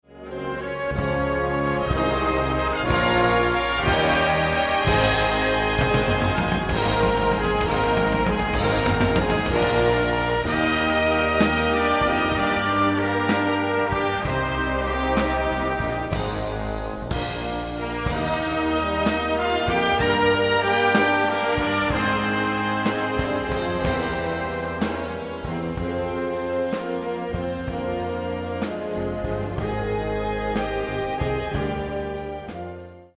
Gattung: Poptitel
Besetzung: Blasorchester